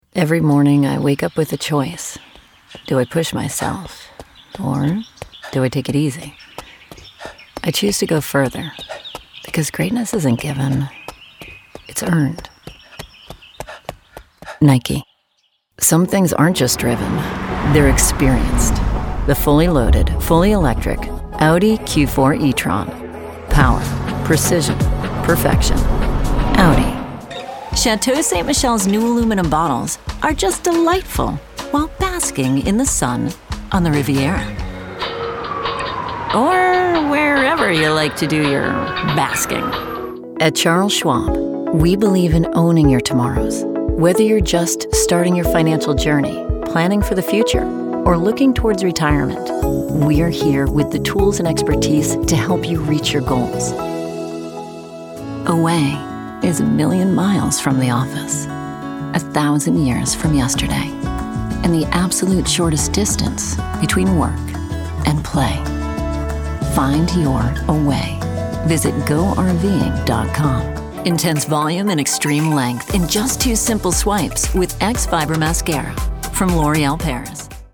Chaleureux
Amical
Bien informé